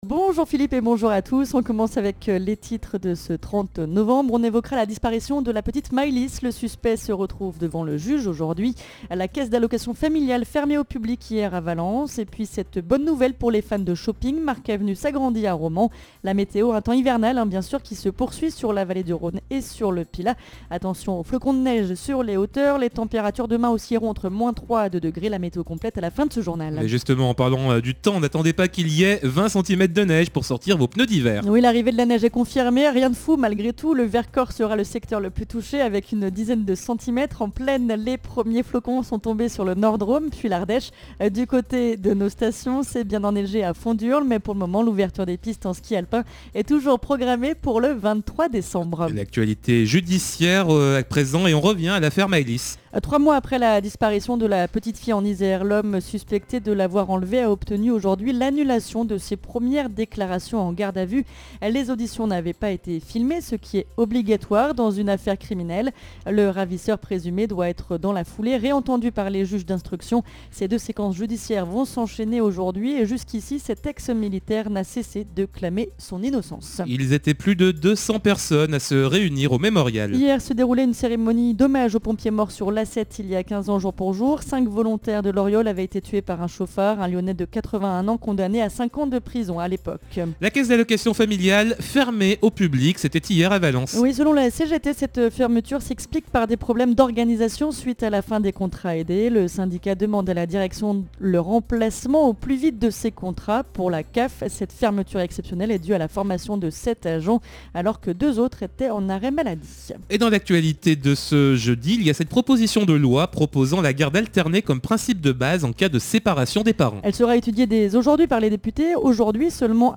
in Journal du Jour - Flash